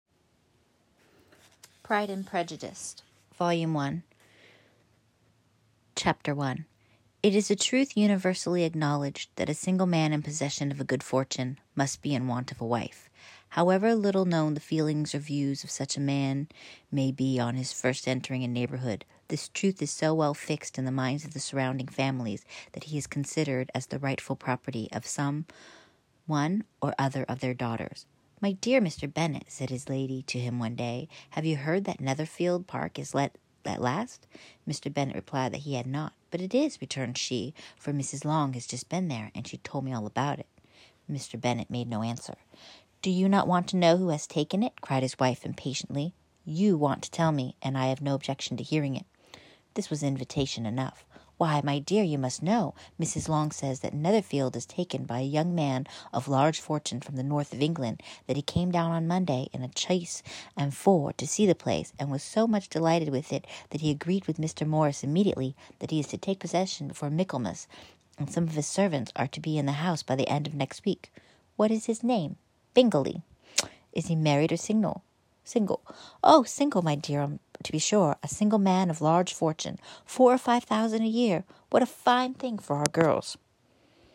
PrideAndPrejudiceWhoop.m4a I never noticed, but I pronounce imaginary “Ts” when I’m reading things out loud.
PrideAndPrejudiceWhoop.m4a